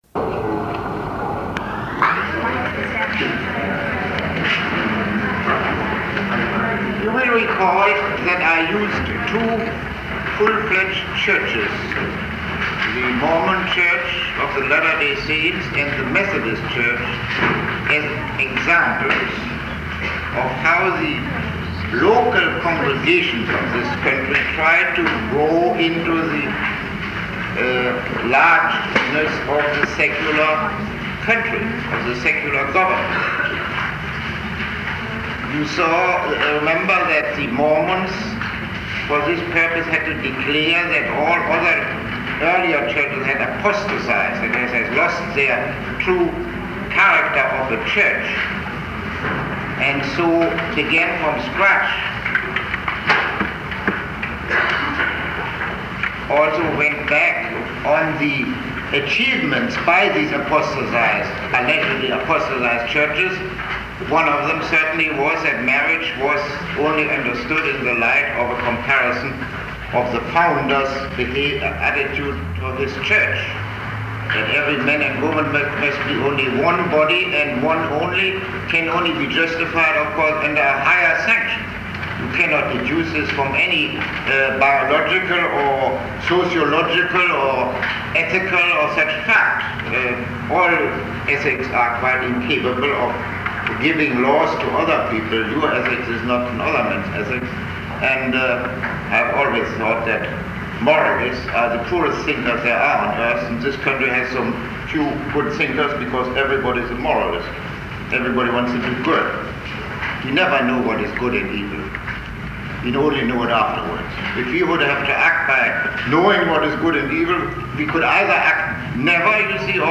Lecture 30